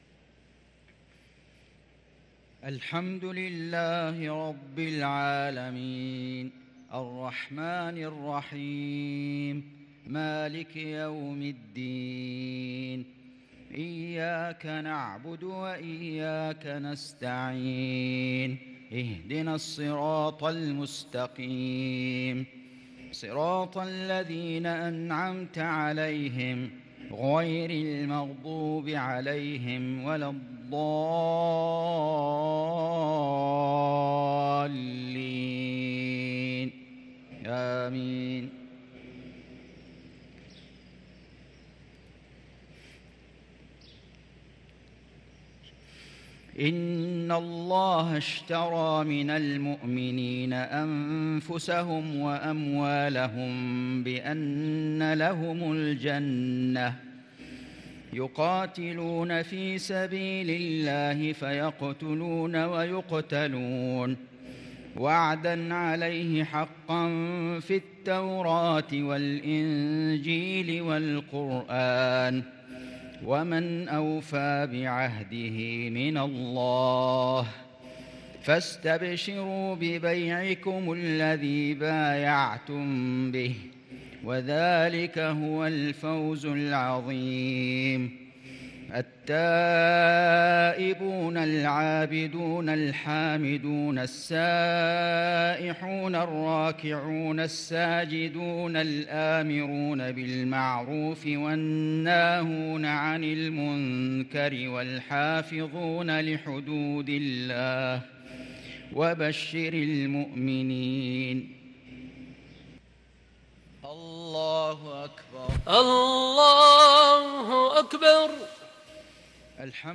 صلاة المغرب للقارئ فيصل غزاوي 29 رمضان 1443 هـ
تِلَاوَات الْحَرَمَيْن .